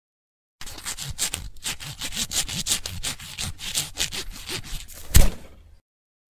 Звуки рвущейся веревки или каната, натягивание и связывания для монтажа видео в mp3
3. Звук, где режут веревку в натяжении ножом, она рвется